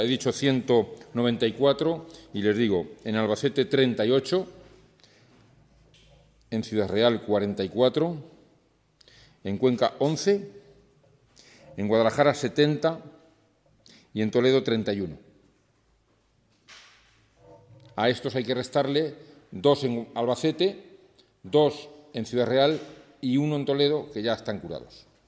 "Estamos subiendo en una semana donde se está cobrando más importancia, dicho con el desconocimiento que se tiene sobre este virus", ha dicho Fernández Sanz.
fernandez_sanz_casos_totales_y_curados.mp3